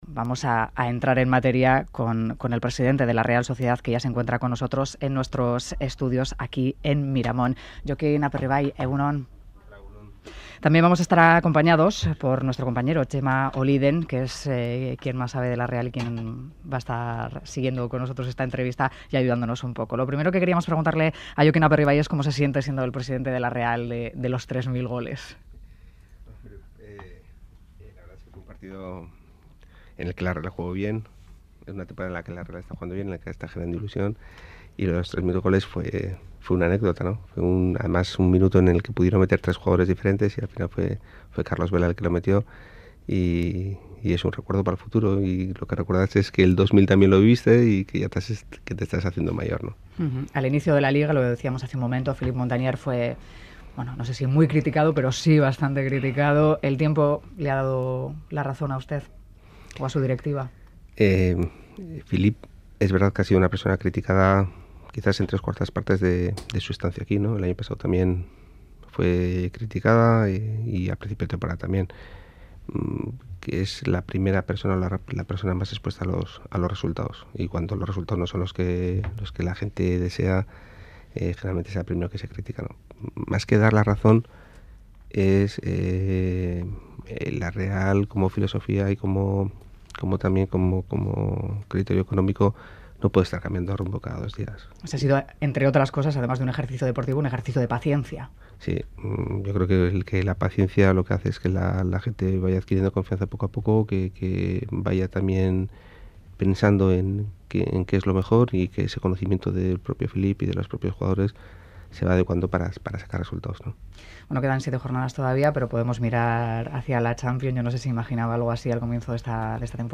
El programa 'Boulevard' de Radio Euskadi ha entrevistado a Jokin Aperribay, empresario nacido en Deba y actual presidente de la Real Sociedad de Fútbol.